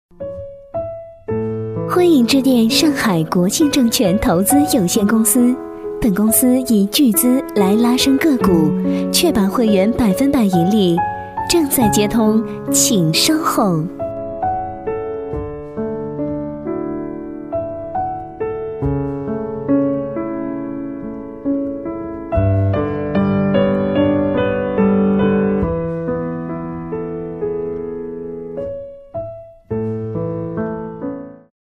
女声配音
彩铃女国47A